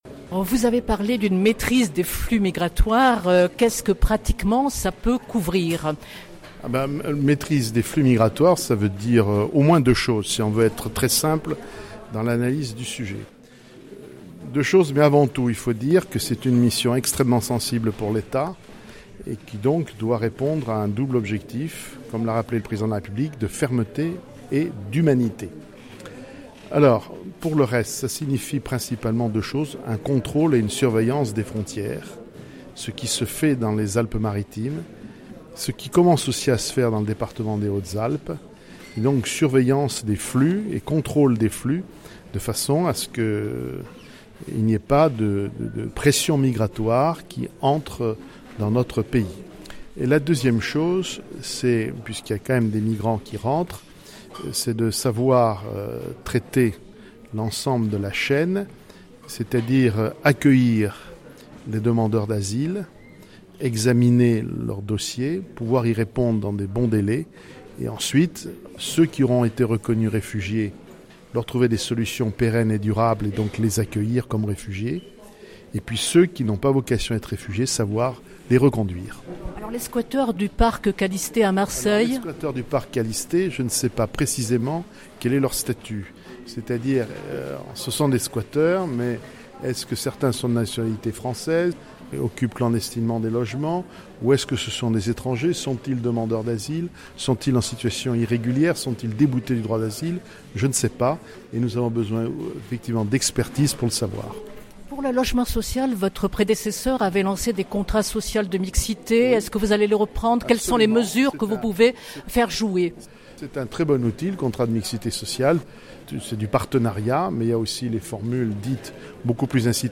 Entretien
Reportage
ste-019_prefet_pierre_dartout_voeux_2018_23_01_18.mp3